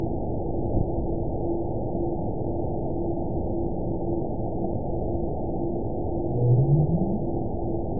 event 922851 date 04/19/25 time 22:56:39 GMT (1 week, 5 days ago) score 9.63 location TSS-AB02 detected by nrw target species NRW annotations +NRW Spectrogram: Frequency (kHz) vs. Time (s) audio not available .wav